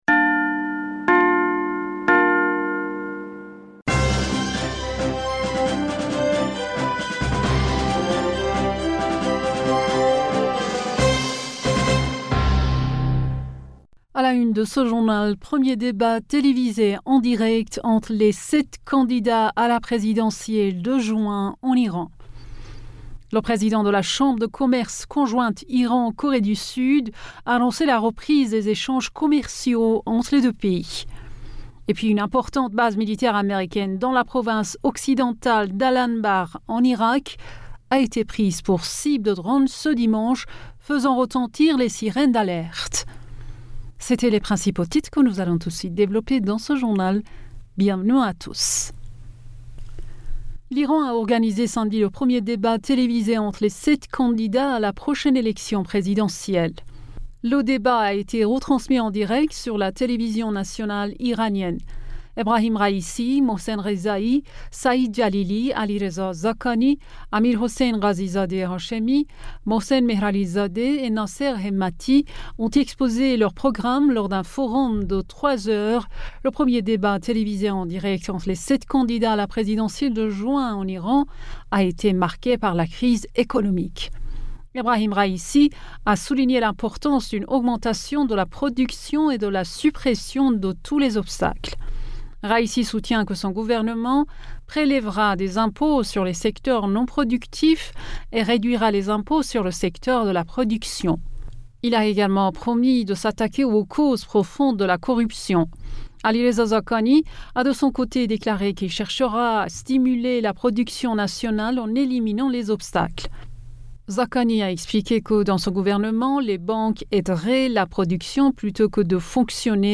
Bulletin d'information du 06 Juin 2021